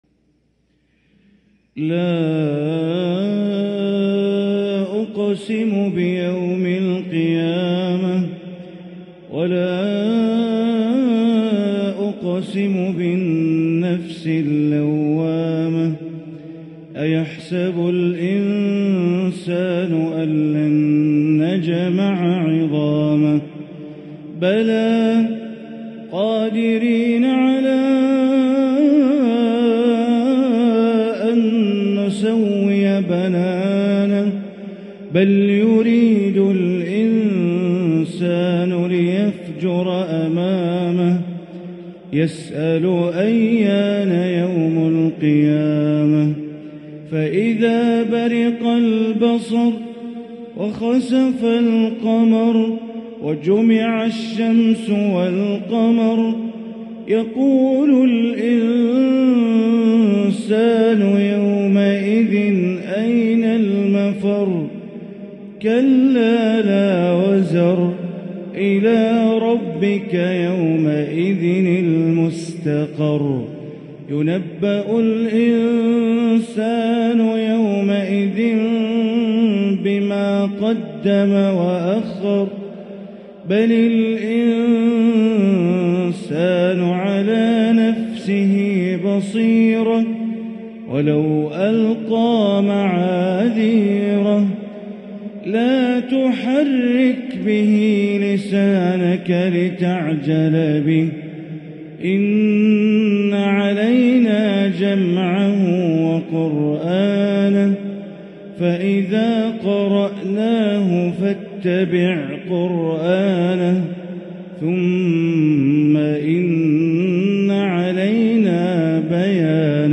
سورة القيامة > مصحف الحرم المكي > المصحف - تلاوات بندر بليلة